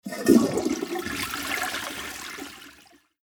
Toilet.mp3